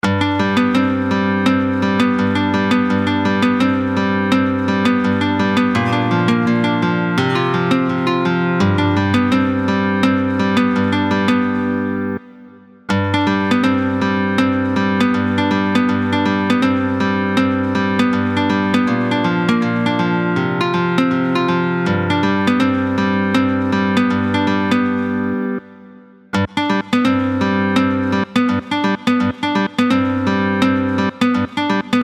en binaire et en ternaire